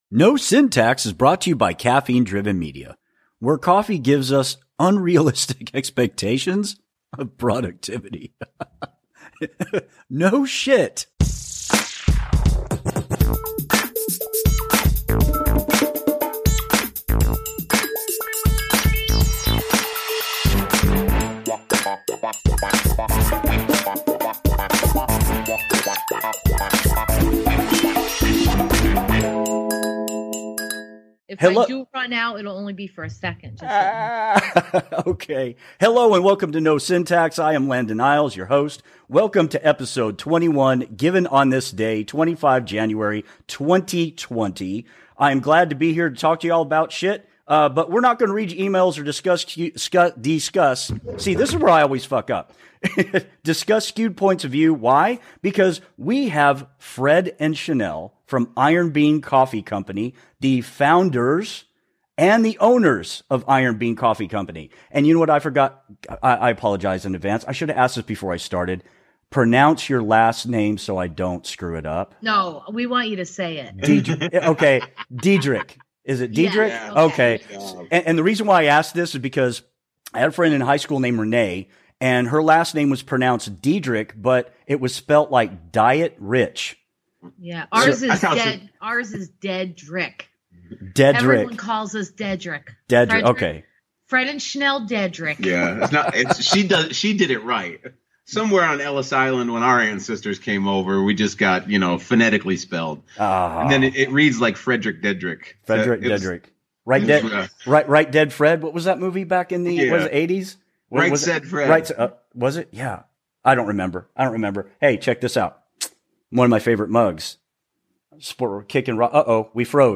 On this weeks show I had the honor of having a conversation, less the interview